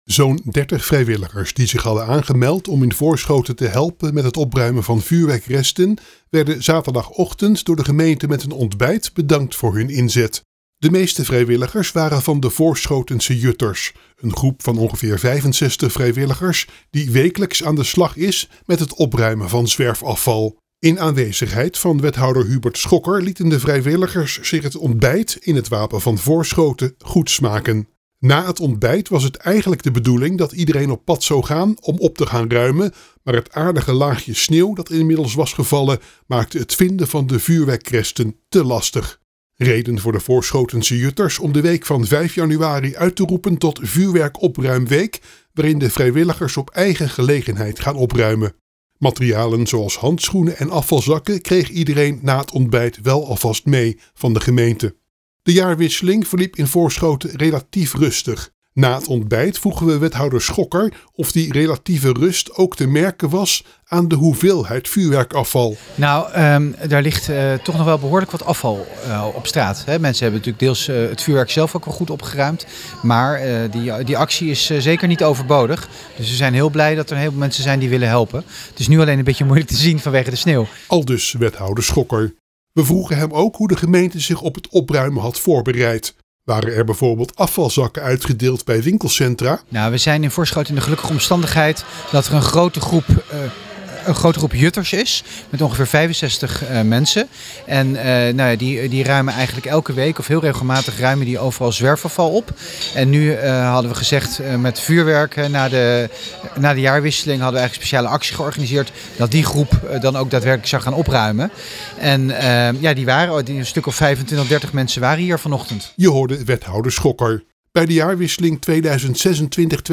AUDIO: Verslaggever